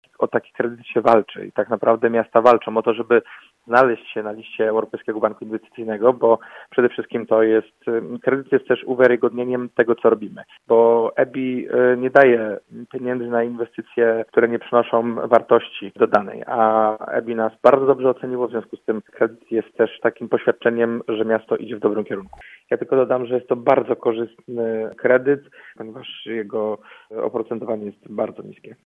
Mówi prezydent Jacek Wójcicki: